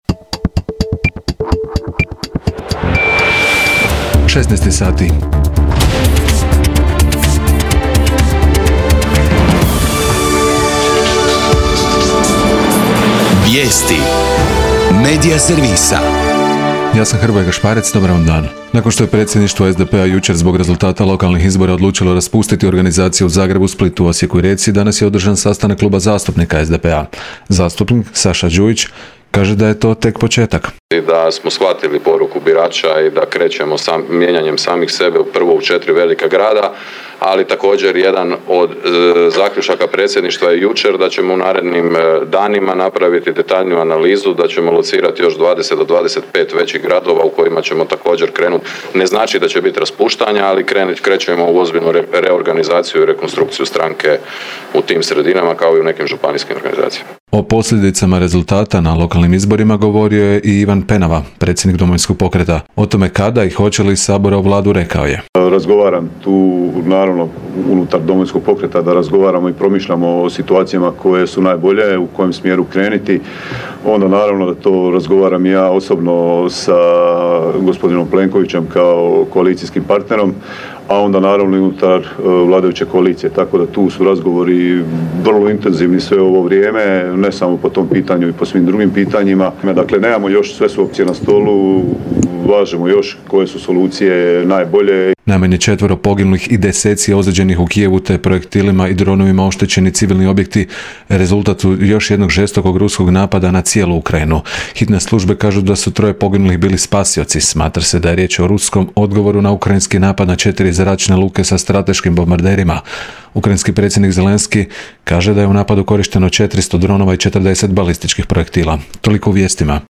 VIJESTI U 16